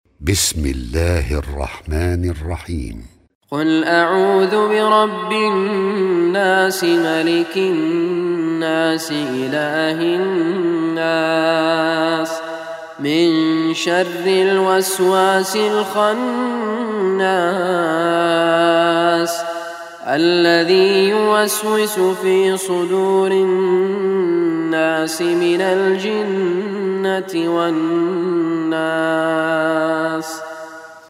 Surah Nas MP3 Recitation by Raad Kurdi
Surah Nas, is last surah of Holy Quran. Listen audio recitation of Surah Nas by Sheikh Raad Al Kurdi.